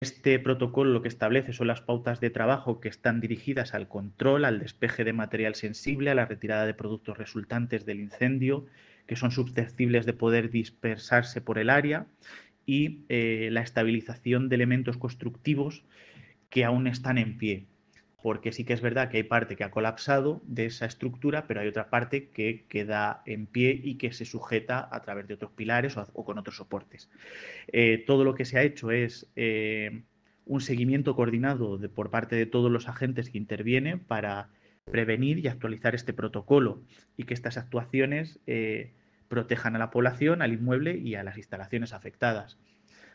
Declaraciones del alcalde Miguel Aparicio 2